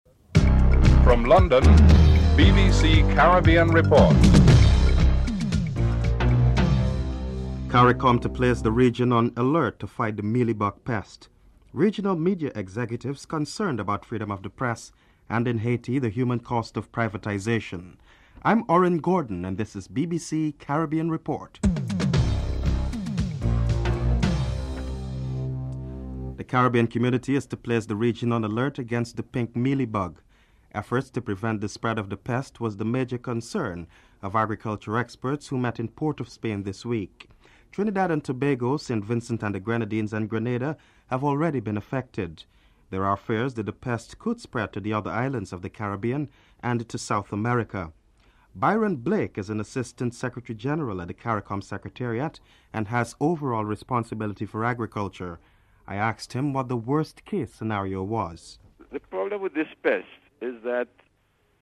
1. Headlines (00:00-00:25)
3. The European Commission has approved a worldwide ban on British beef. European Commissioner Sir Leon Brittan is interviewed (03:35-04:56)